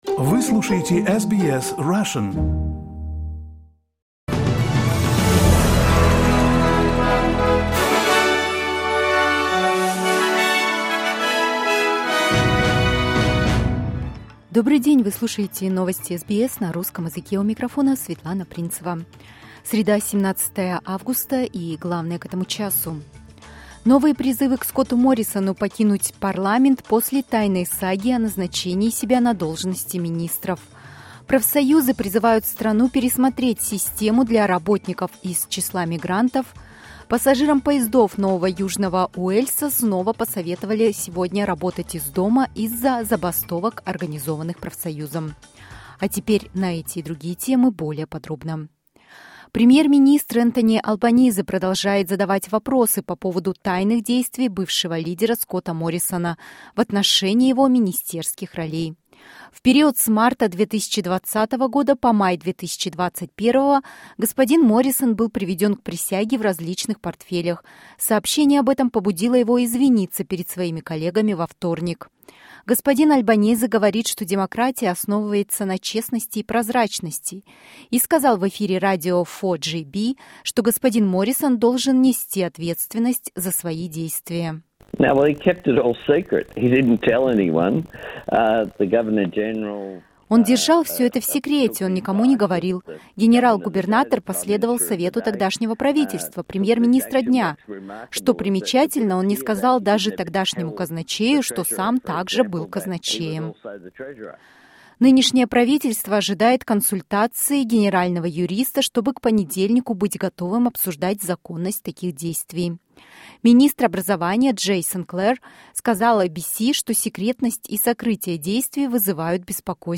SBS News in Russian - 17.08.22